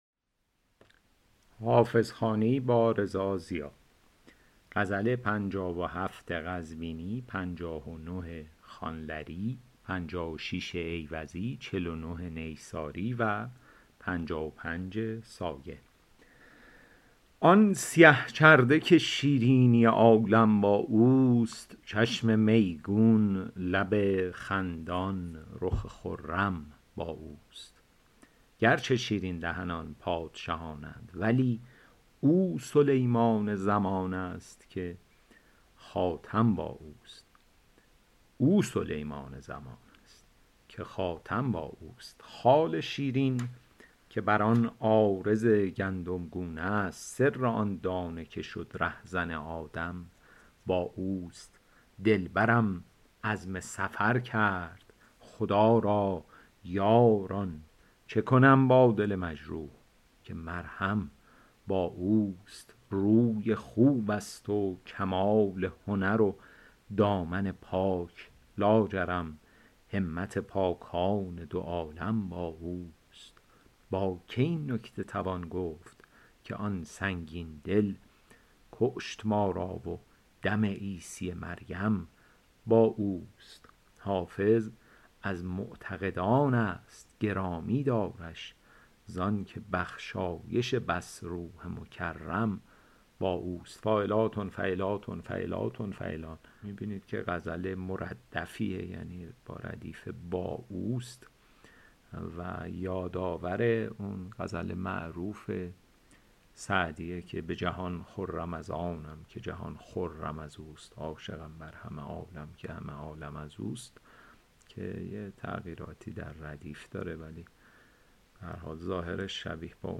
شرح صوتی